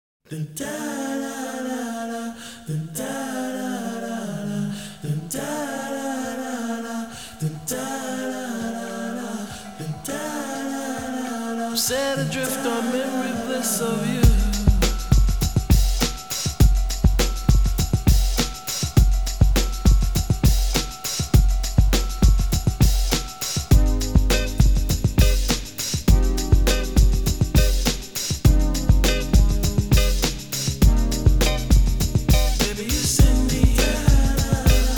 Жанр: Хип-Хоп / Рэп / R&B / Соул